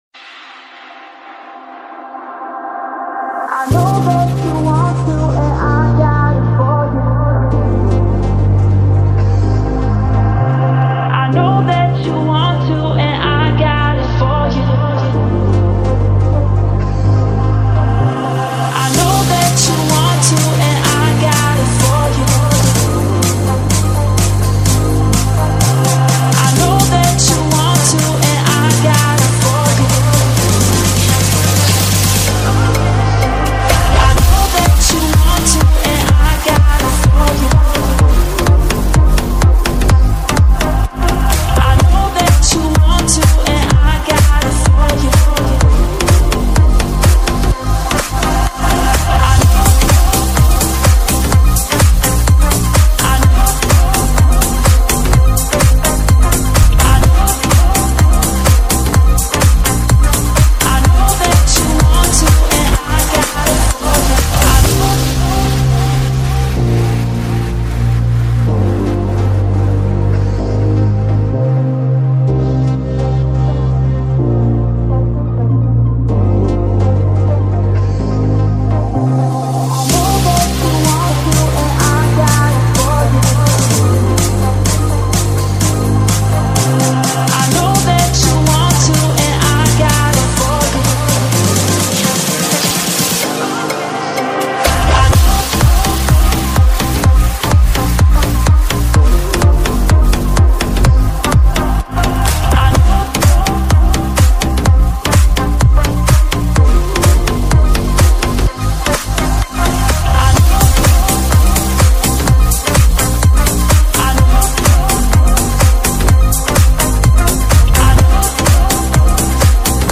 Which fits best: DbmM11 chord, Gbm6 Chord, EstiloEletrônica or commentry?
EstiloEletrônica